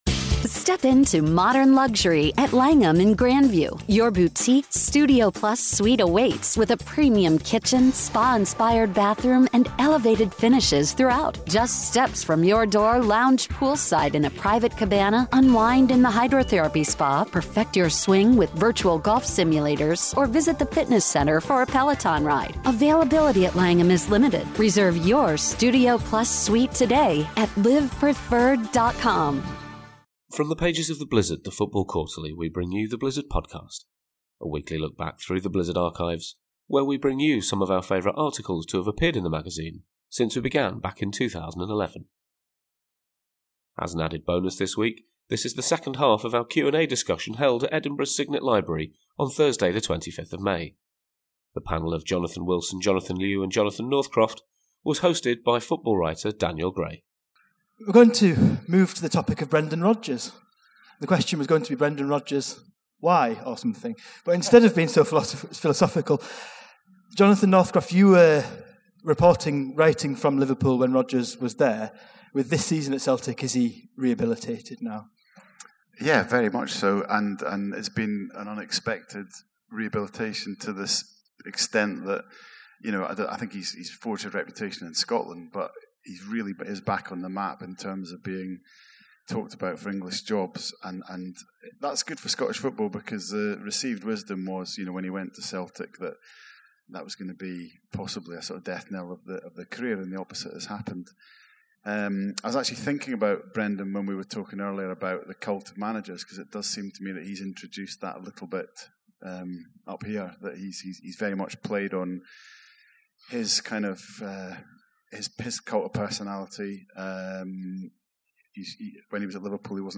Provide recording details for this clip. Despite being on a podcast hiatus while we work on some exciting things behind the scenes we still found time to do a live recording of our Q&A event in Edinburgh last week. This is the second half of that discussion.